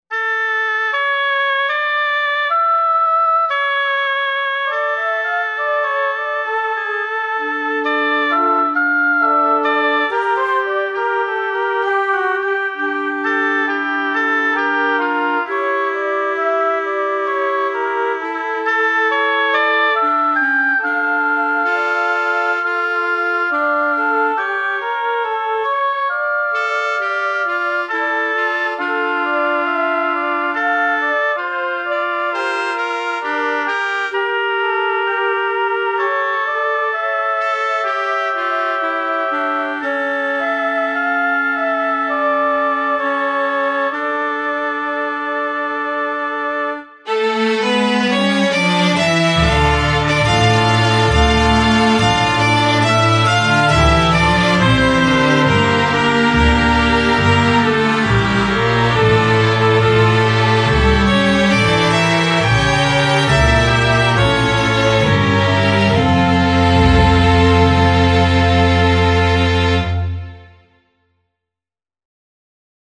religion - cour - roi - reine - traditionnel